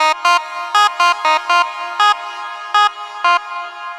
TSNRG2 Lead 005.wav